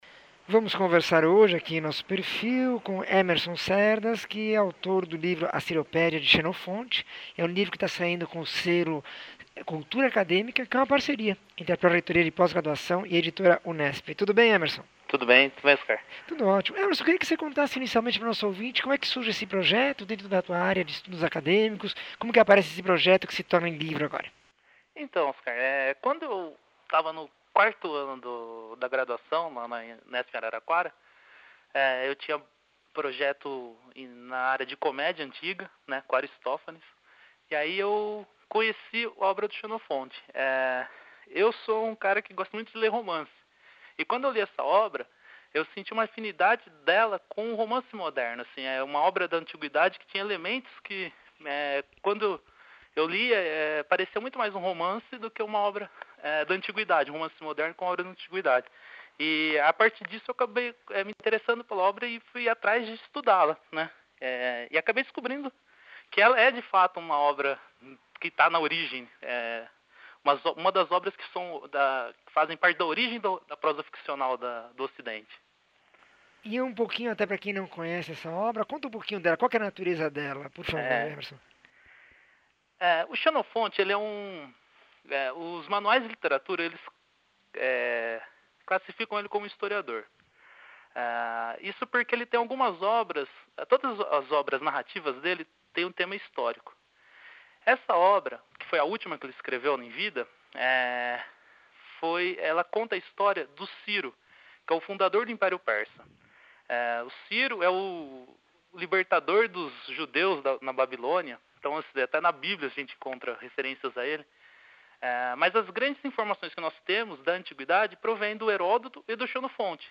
entrevista 1403